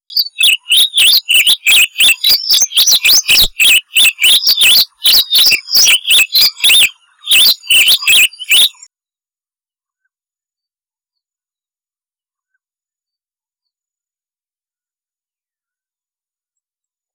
Sporophila collaris - Dominó